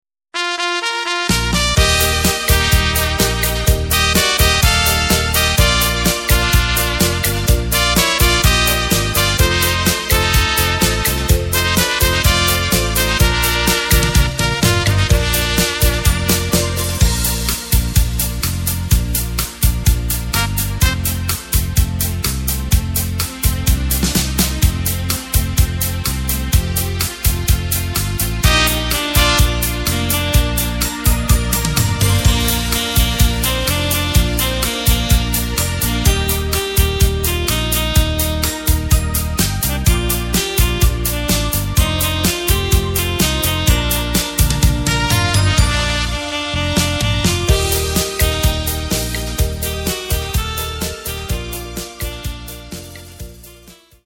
Tempo:         126.00
Tonart:            Bb
Schlager Instrumental
Playback mp3 Demo